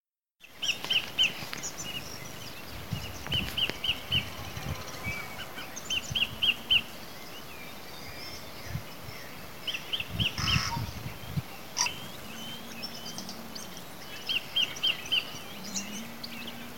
Alción Sagrado (Todiramphus sanctus)
País: Nueva Zelanda
Localidad o área protegida: Lake Ellesmere
Condición: Silvestre
Certeza: Vocalización Grabada
sacred-kingfisher.mp3